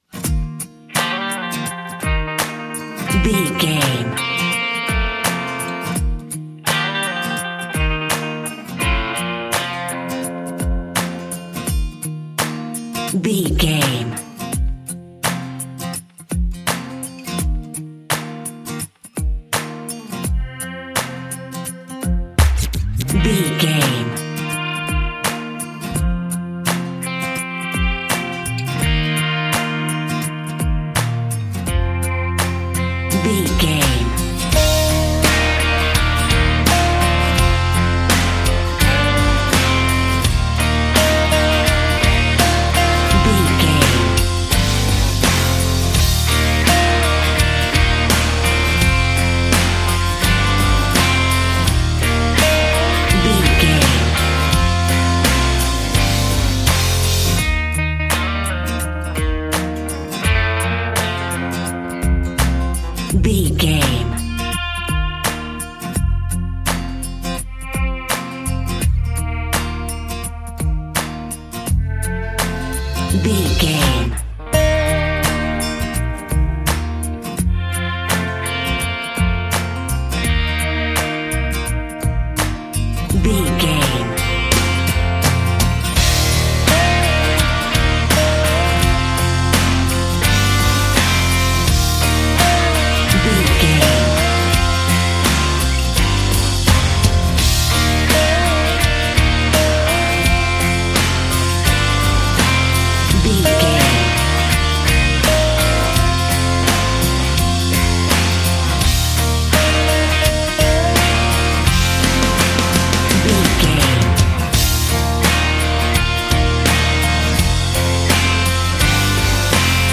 Ionian/Major
E♭
acoustic guitar
electric guitar
drums
bass guitar